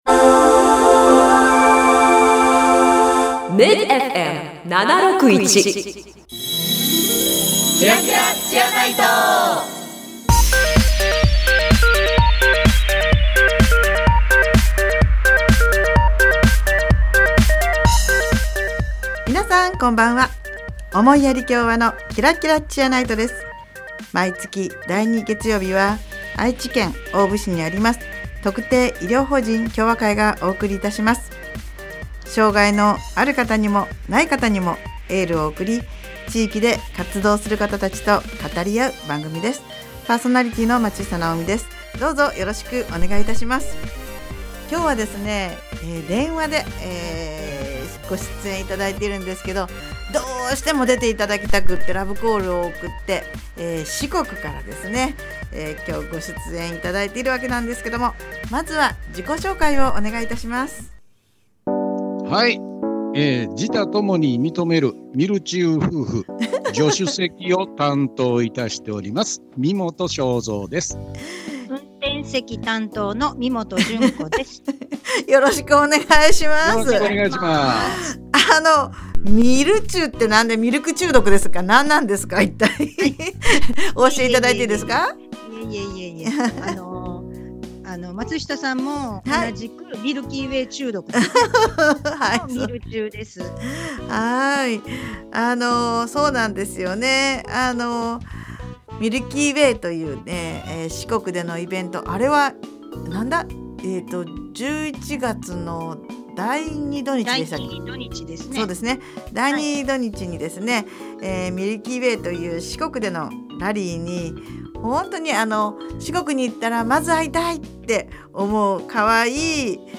【放送時間 】第2月曜日 19：00 MID-FM 76.1 【ゲスト】
この番組では、地域の医療・福祉に携わる方々と語り合い、偏見にさらされやすい障がいのある方に心からのエールを送ります。 毎回、医療・福祉の現場に直接携わる方などをゲストに迎え、現場での色々な取り組みや将来の夢なども語り合います。